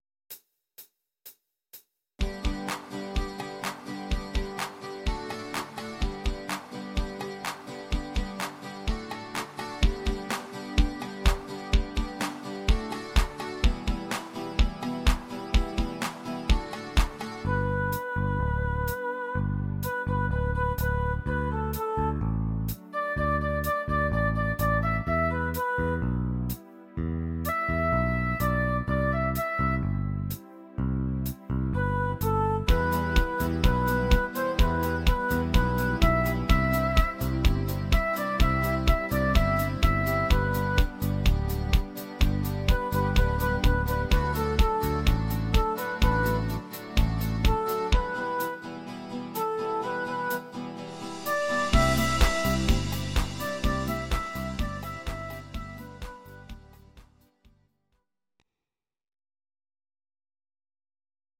These are MP3 versions of our MIDI file catalogue.
Please note: no vocals and no karaoke included.
radio remix